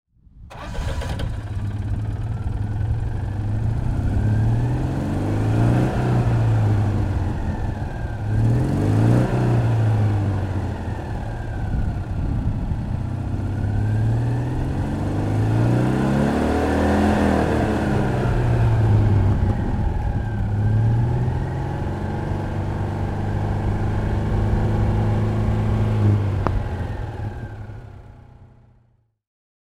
Citroën 2 CV "Scotch" (1985) - Starten und Leerlauf
Citroen_2_CV_1985.mp3